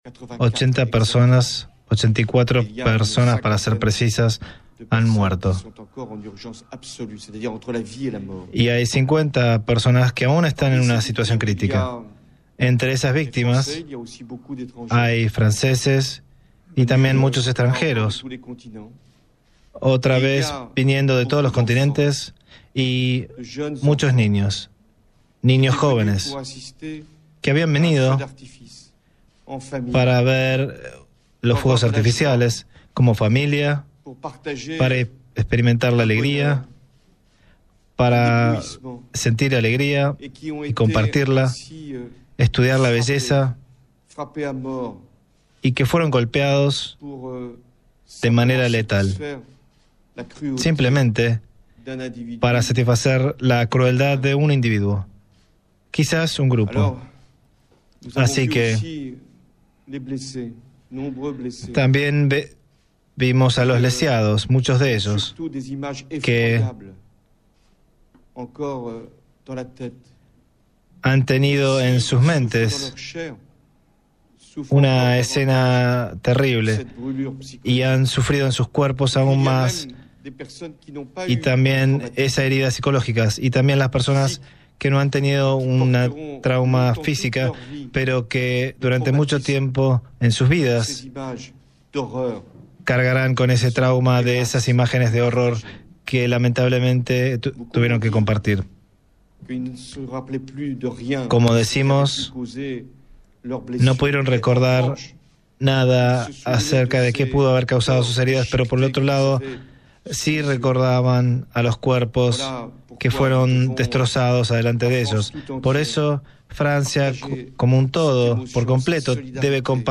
El presidente francés François Hollande, brindó conferencia de prensa para hablar sobre el atentado terrorista que se perpetró en la noche de ayer en la ciudad de Niza, Francia. “Entre las víctimas hay muchos jóvenes y niños”, anunció Hollande.